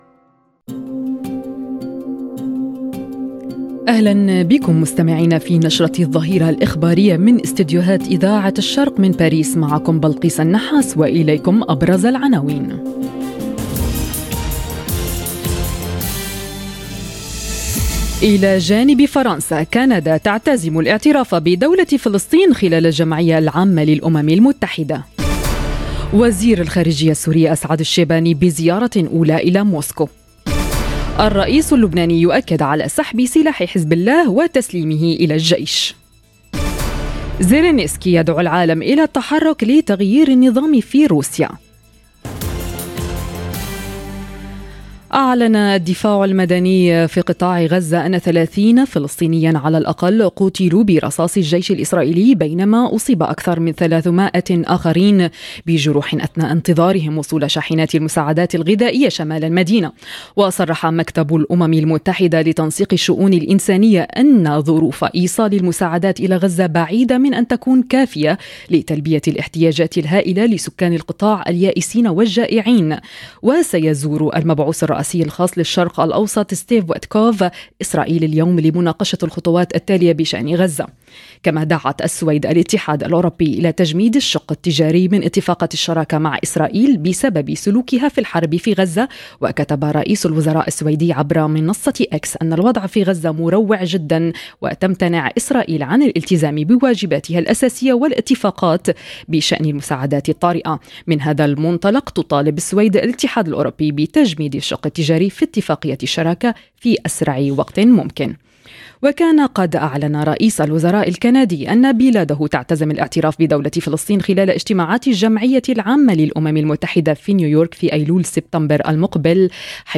نشرة اخبار الظهيرة: كندا تنضم إلى الدول التي تعتزم الاعتراف بدولة فلسطين، ووزير الخارجية السوري في موسكو - Radio ORIENT، إذاعة الشرق من باريس